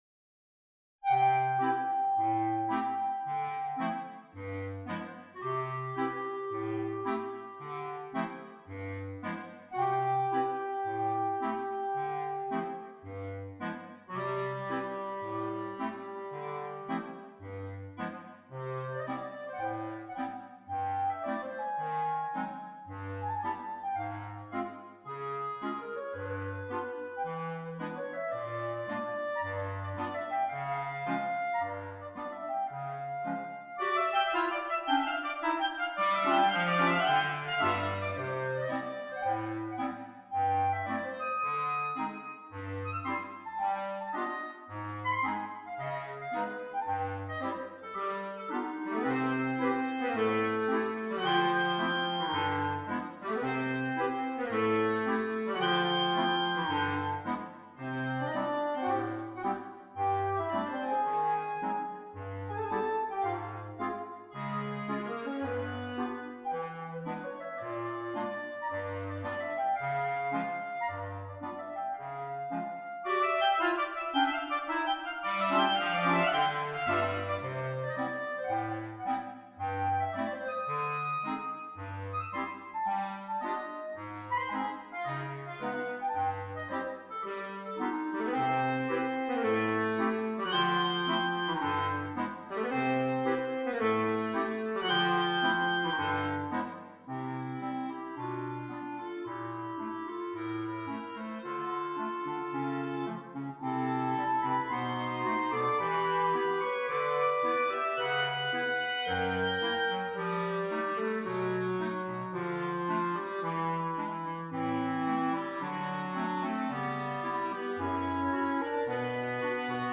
per coro di clarinetti